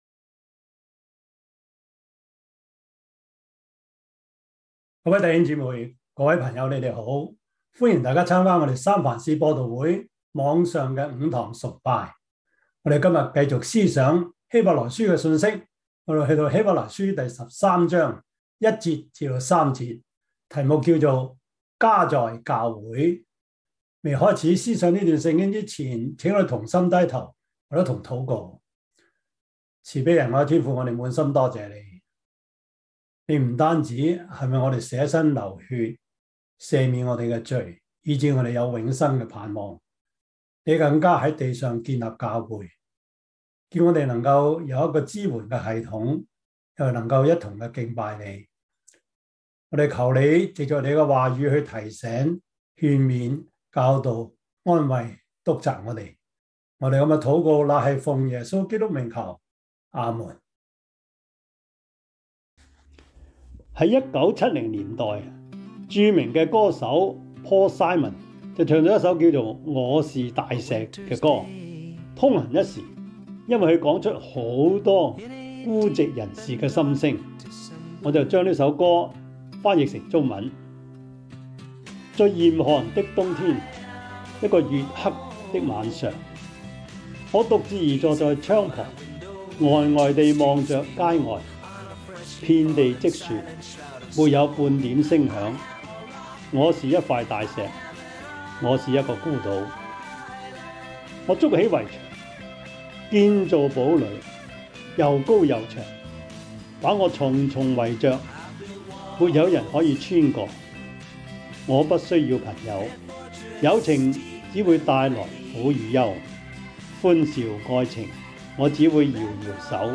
希伯來書 13:1-3 Service Type: 主日崇拜 希 伯 來 書 13:1-3 Chinese Union Version